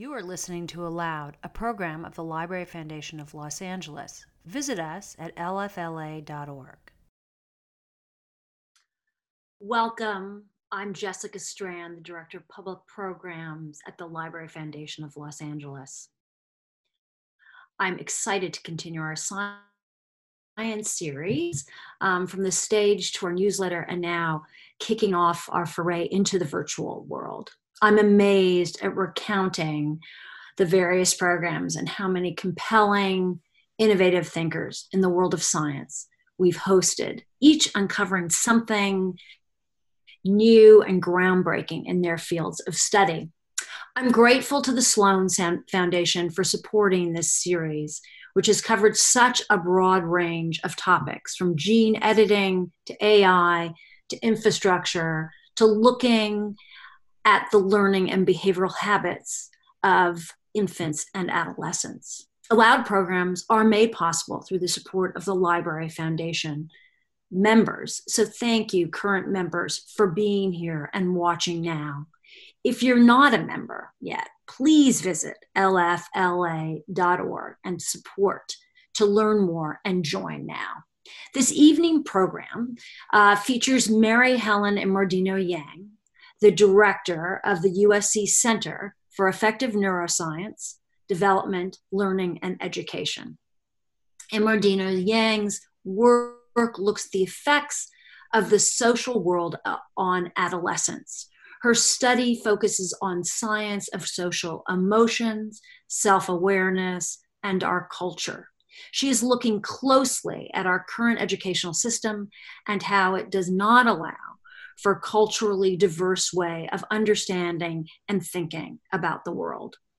In ALOUD’s first live program, we’ll explore the science of virtual learning.